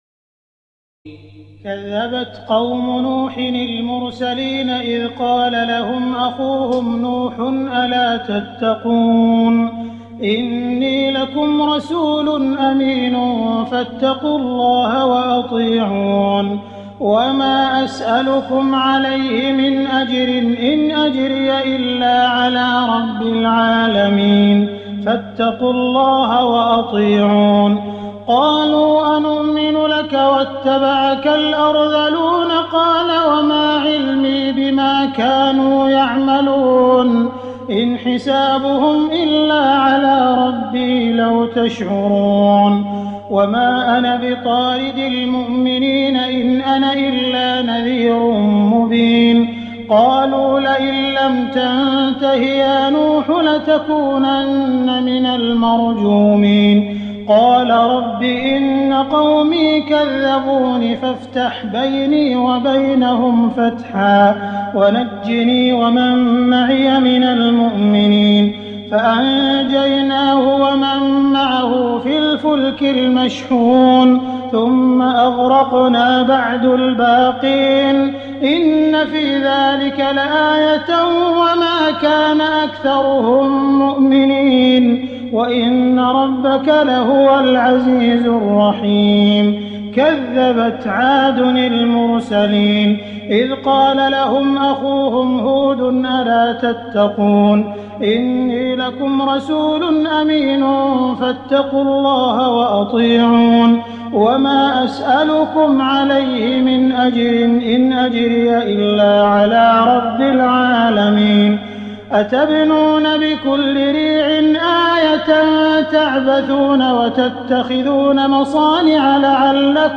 تراويح الليلة الثامنة عشر رمضان 1419هـ من سورتي الشعراء (105-227) والنمل (1-58) Taraweeh 18 st night Ramadan 1419H from Surah Ash-Shu'araa and An-Naml > تراويح الحرم المكي عام 1419 🕋 > التراويح - تلاوات الحرمين